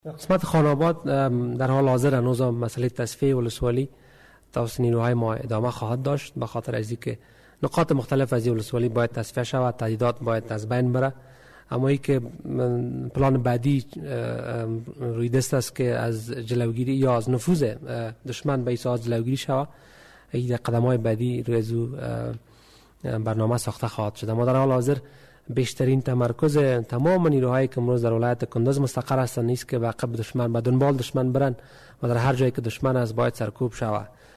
صدیق صدیقی، سخنگوی وزارت داخله افغانستان در مورد پس گیری خان آباد صحبت می کند.